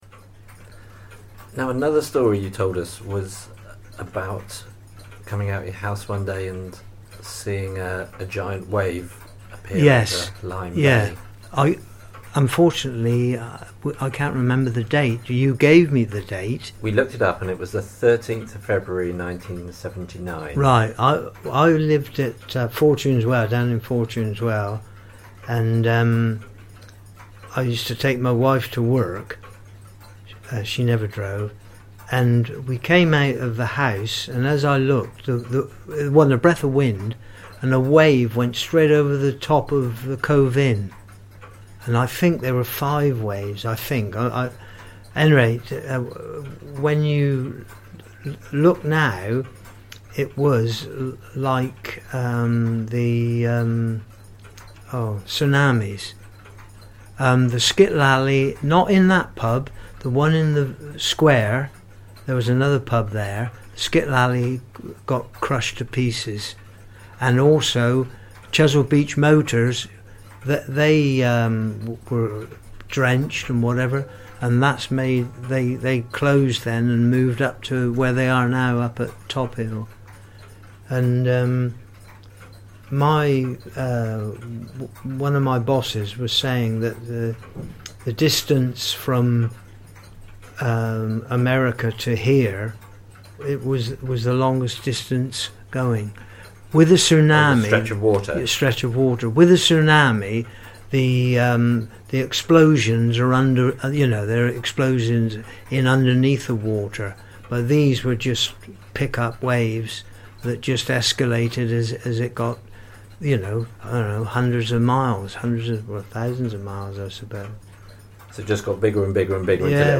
1979 Wave ABSOLUTE THEATRE in partnership with learners from the ISLE OF PORTLAND ALDRIDGE COMMUNITY ACADEMY, recorded these anecdotes, memories and experiences of Portland people.